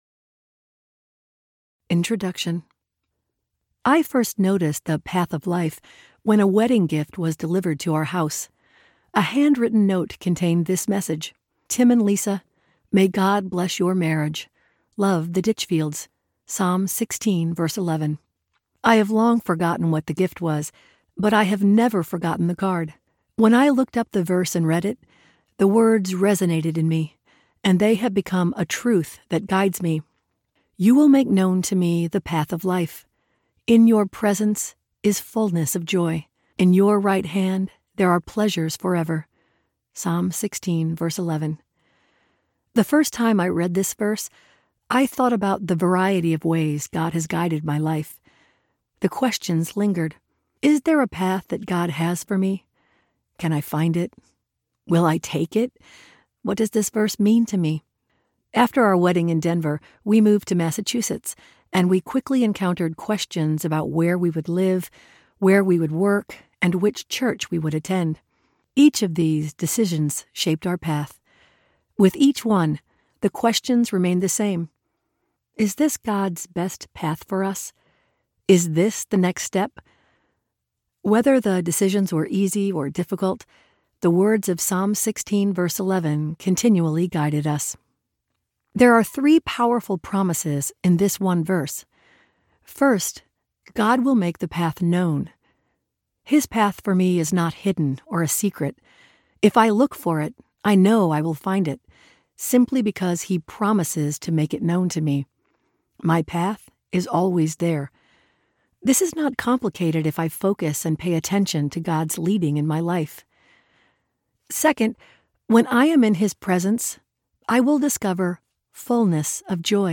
The Path of Life Audiobook
Narrator
6.12 Hrs – Unabridged